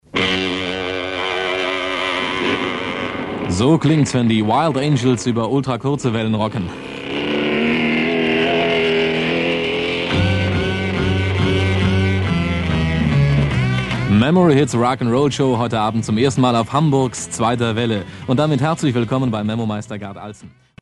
über Ultra Kurze Wellen rocken